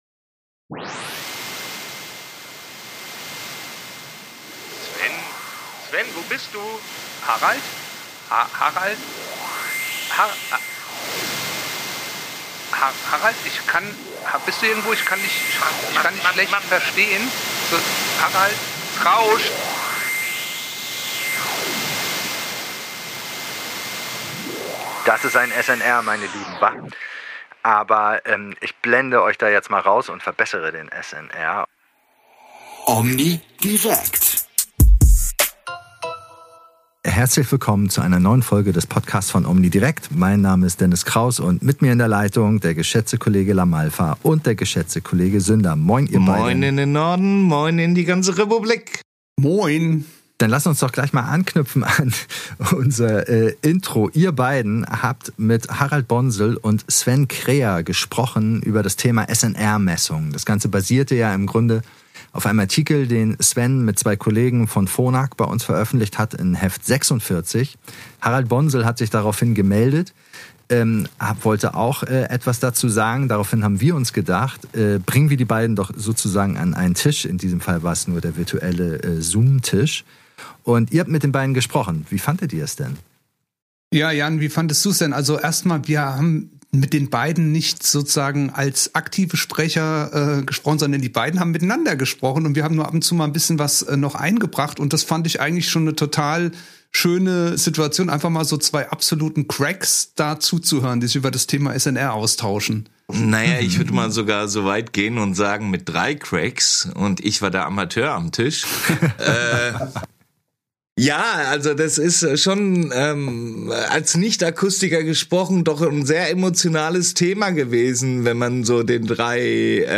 Am Anfang ist der SNR noch gegen die drei OMNIdirekten.